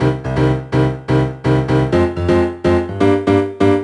cch_synth_loop_chicago_125_Gm.wav